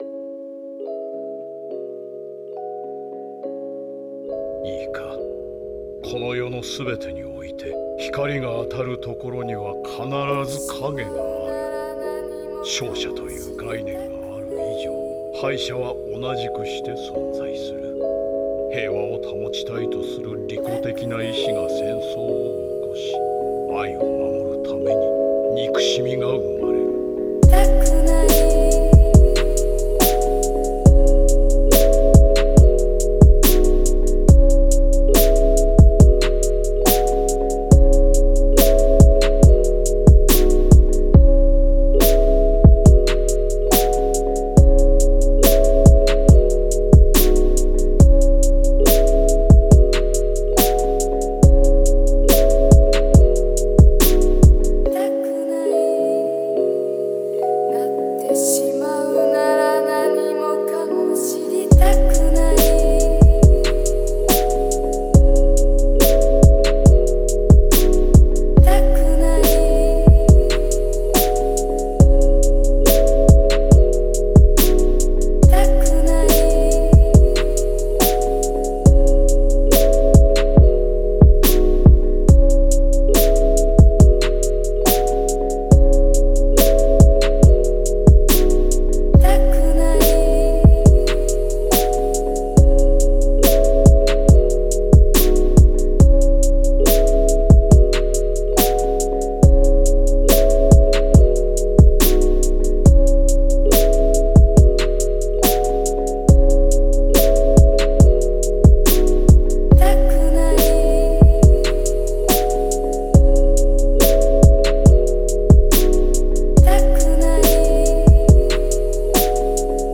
Rap Душевный 140 BPM